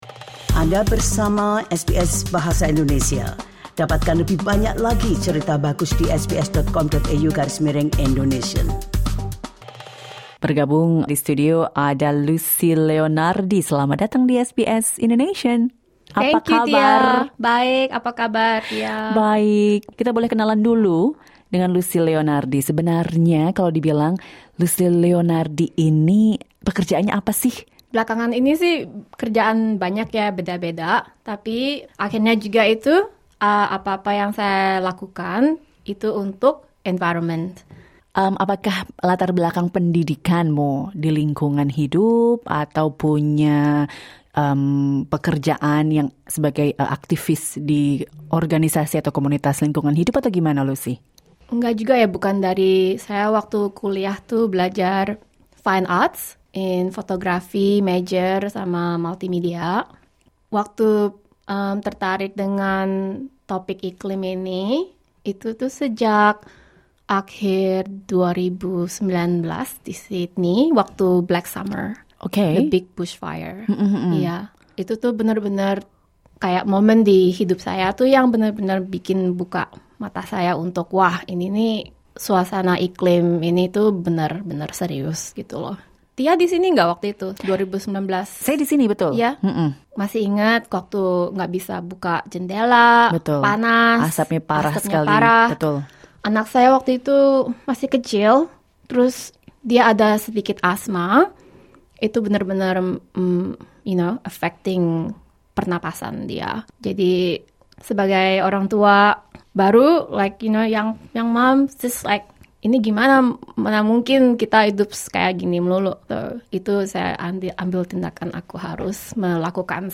In an interview with SBS Indonesian